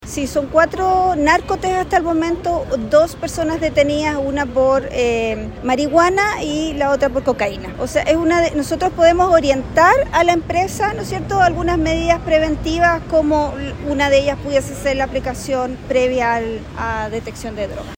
La directora regional de SENDA, Lissy Cerda, afirmó que como organismo pueden orientar a las líneas para prevenir este tipo de situaciones.